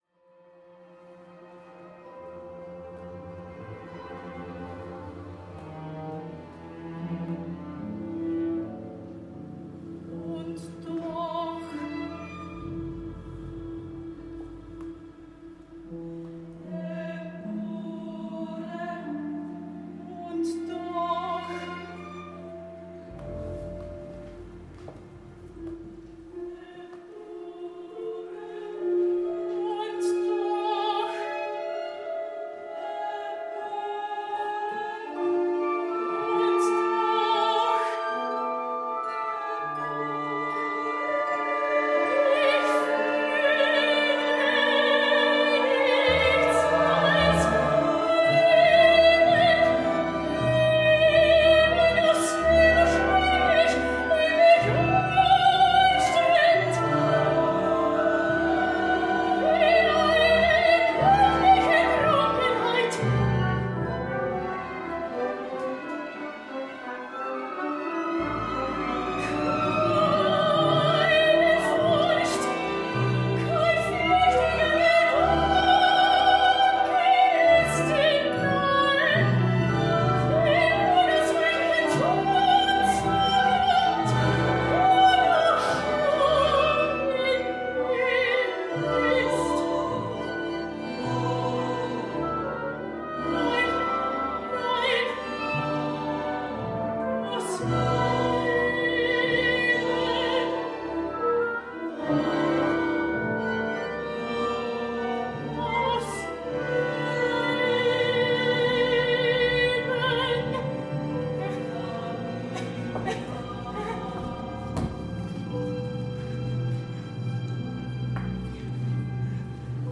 two one-act operas
tenor